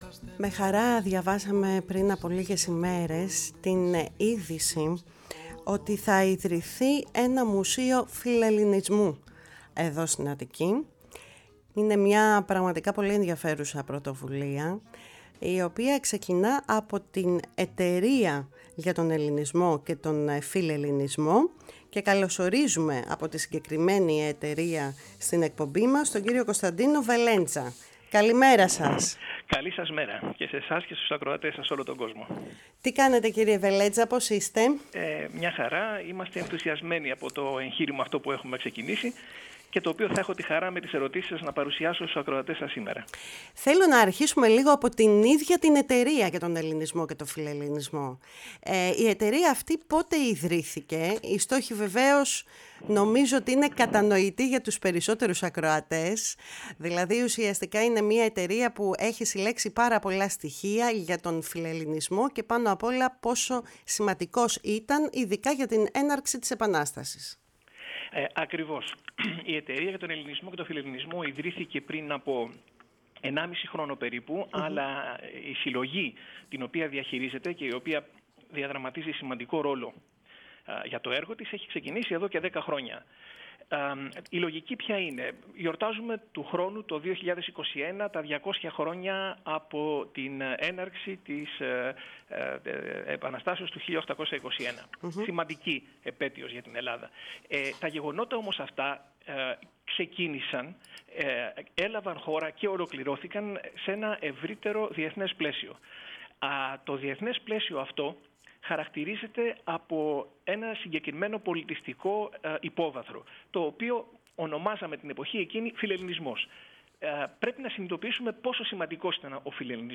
ενώ παρουσίασε συνοπτικά στη διάρκεια της συνέντευξης την ιστορία του Φιλελληνισμού.